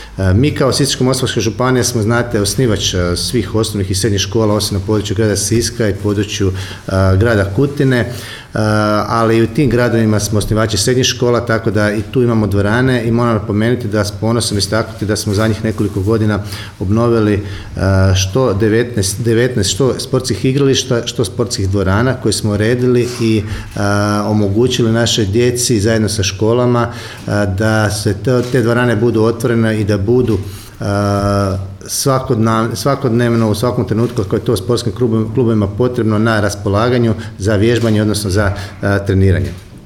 Natjecanja, u sklopu Plazma Sportskih igara, neće se održavati samo u Sisku, nego i u drugim gradovima i općinama na području naše županije, napomenuo je zamjenik župana Mihael Jurić